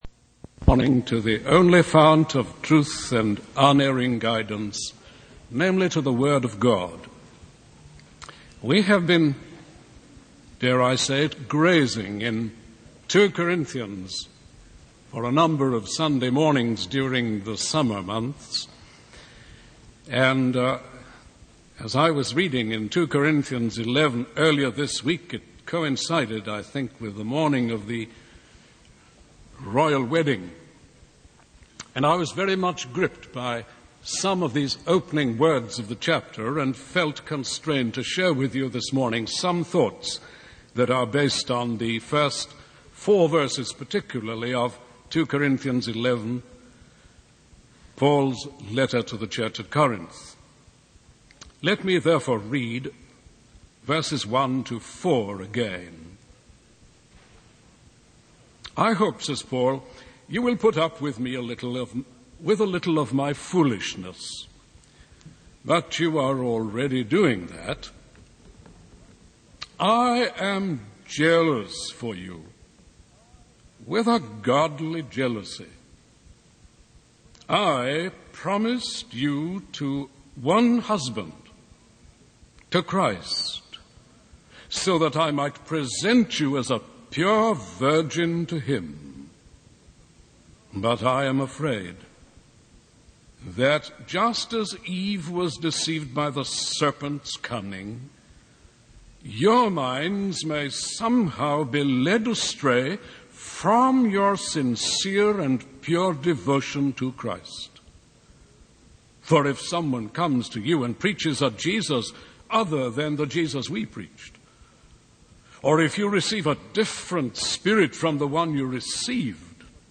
In this sermon, the preacher focuses on Paul's letter to the church at Corinth in 2 Corinthians 11.